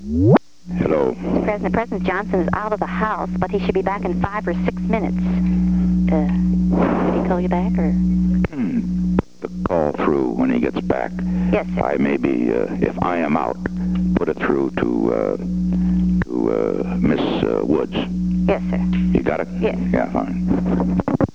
Secret White House Tapes
Location: White House Telephone
The White House operator talked with the President.